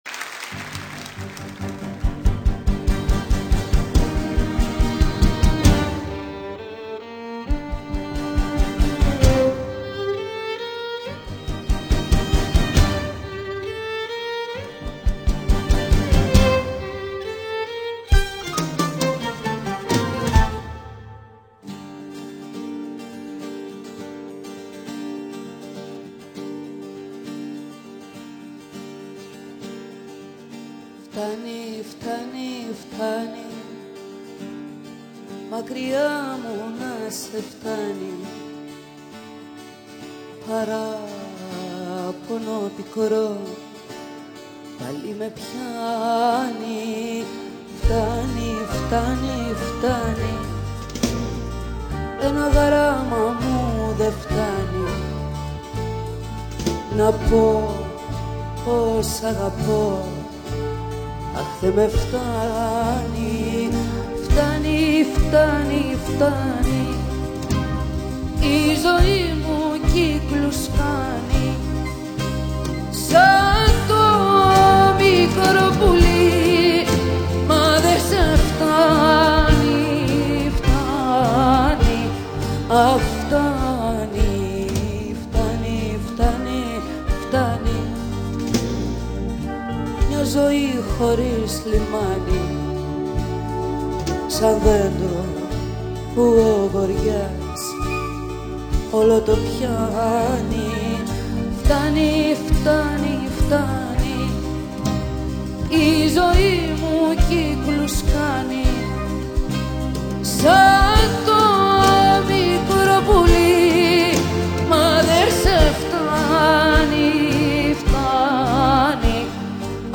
Live 96